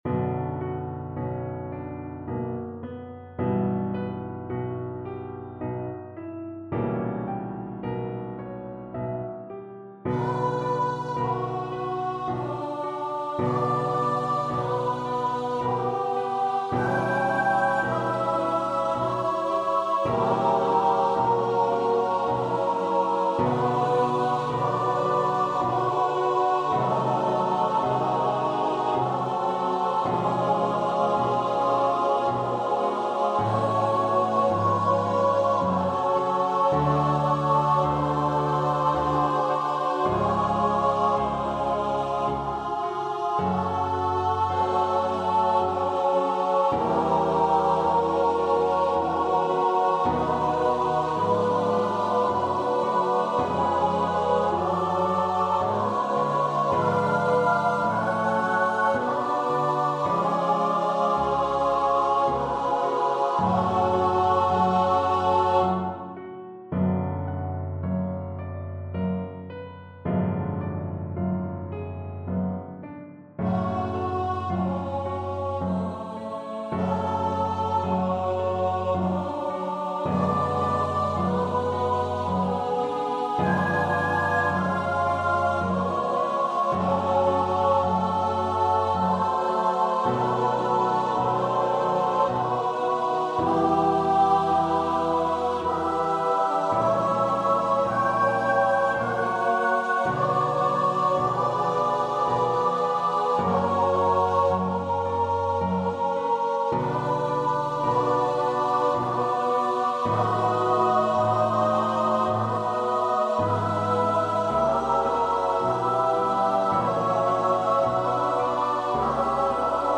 Free Sheet music for Choir (SSATB)
Choir  (View more Intermediate Choir Music)
Classical (View more Classical Choir Music)